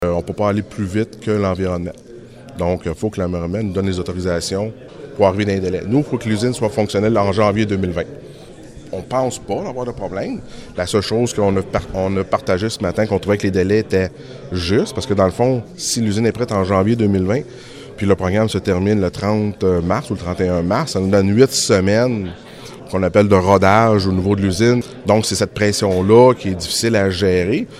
Il a toutefois profité de la présence des ministres pour exprimer sa crainte de ne pas être en mesure de livrer le projet dans les délais imposés par le Fonds pour l’eau potable et le traitement des eaux usées, en raison des exigences environnementales :